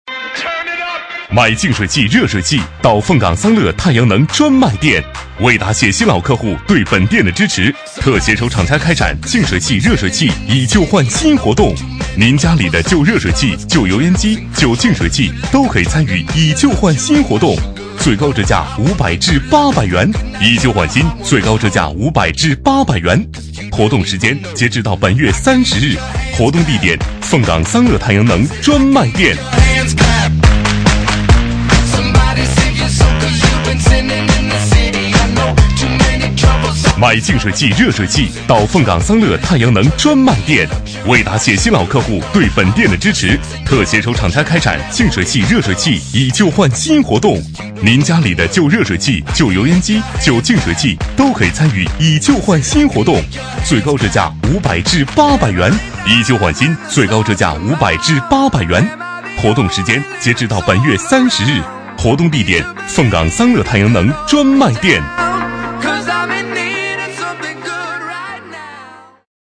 B类男01
【男1号促销】太阳能专卖店